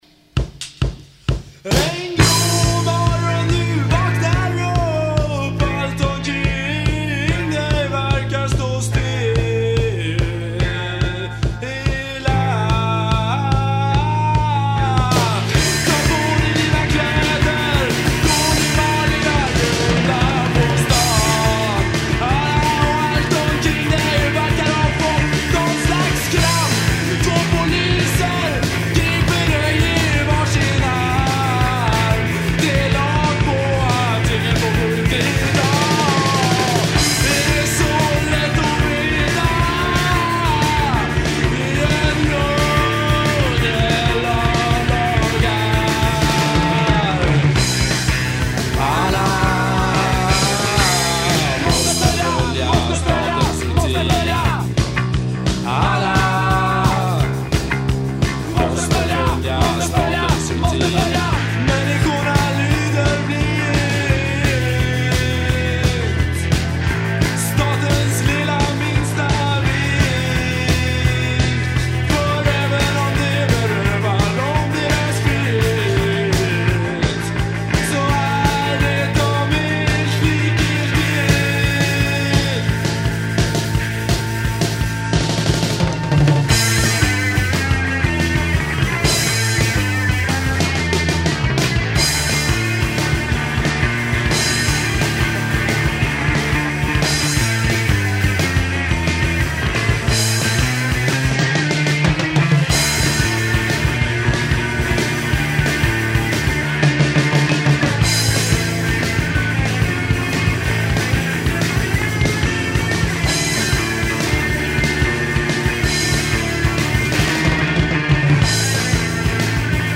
Bass, b-Stimme
Gitarre, b-Stimme
Trommel